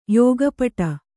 ♪ yōga paṭa